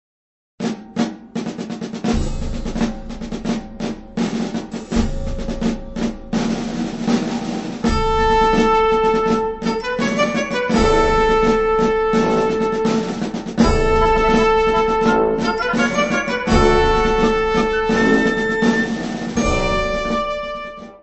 Music Category/Genre:  Soundtracks, Anthems, and others